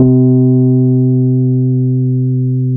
RHODESY 1.wav